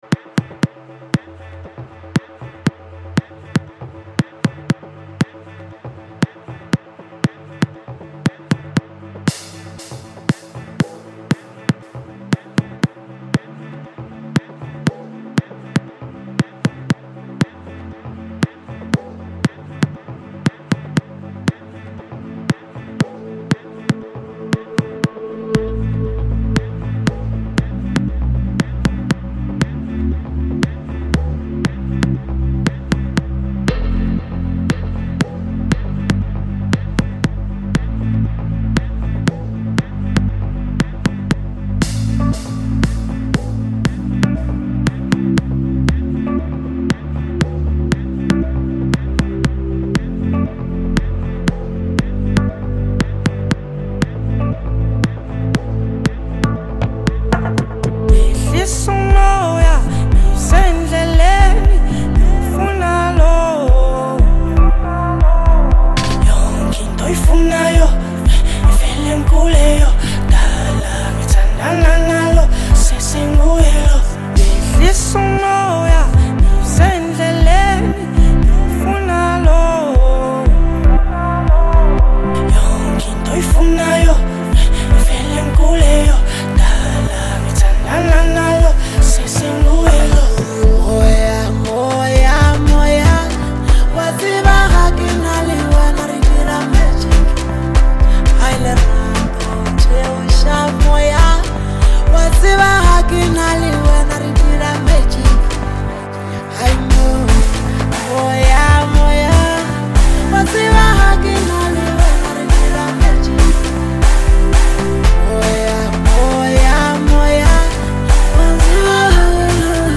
Home » Amapiano » DJ Mix » Maskandi